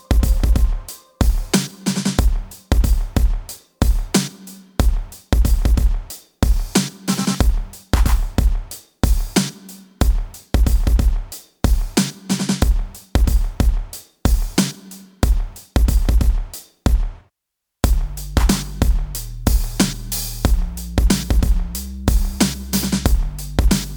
Minus All Guitars Pop (2010s) 3:46 Buy £1.50